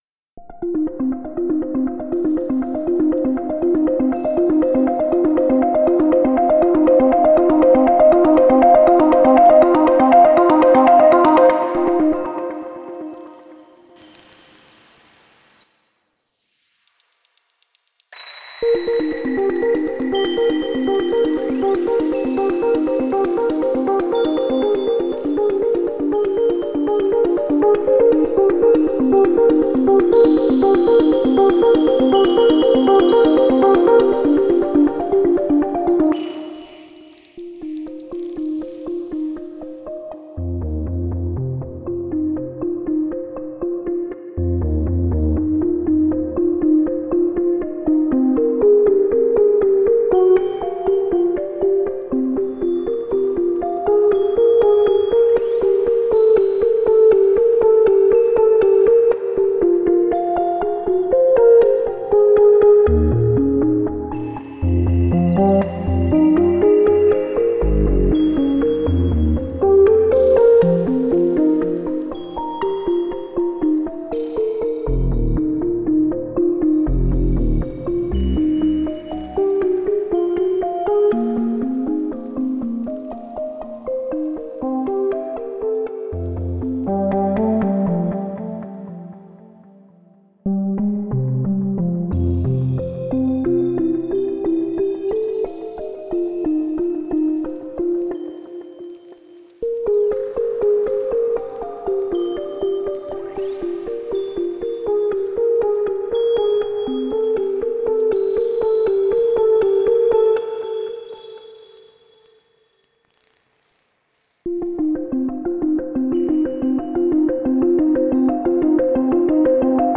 World-building music encourages us to rethink and reflect on the important role of imagination, opening up new possibilities for imagined futures – at the Phytotron, this was the future of the tropical plants.